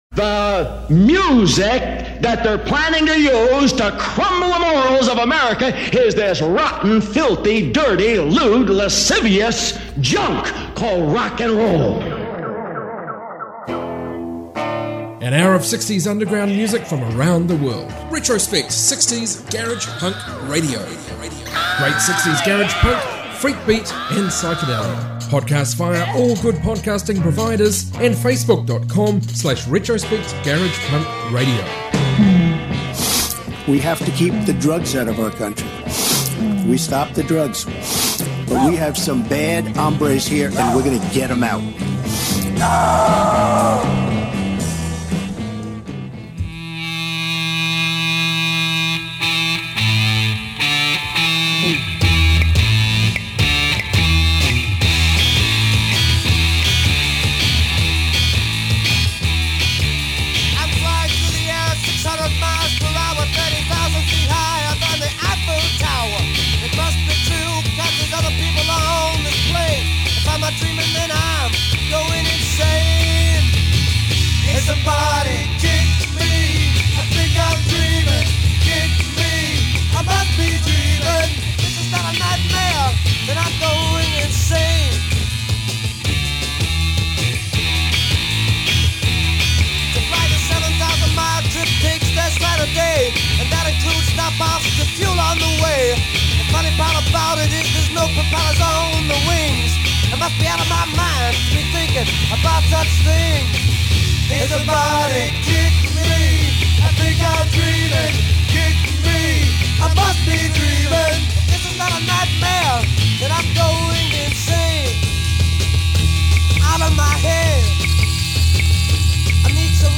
60s garage rock, garage punk, proto-punk, freakbeat and psychedelia